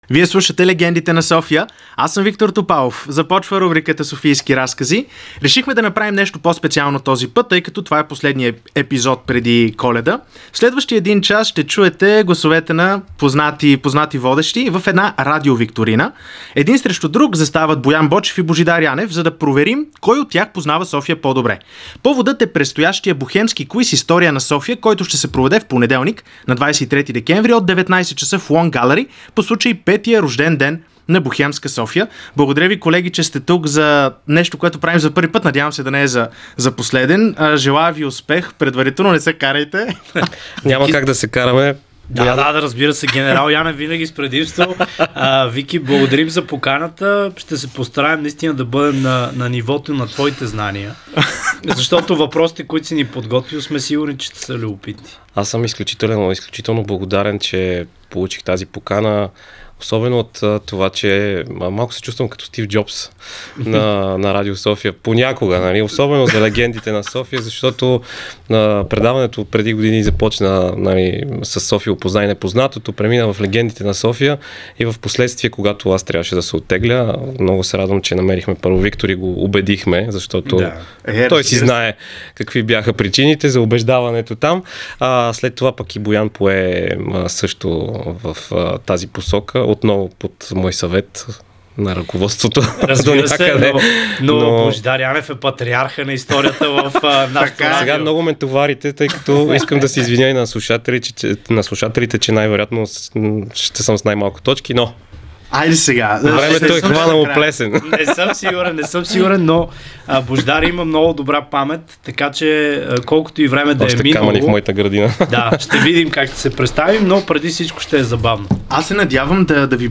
Време е за куиз – и то не какъв да е, а бохемски – в Софийски разкази, част от Легендите на София